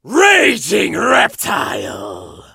monster_stu_ulti_vo_01.ogg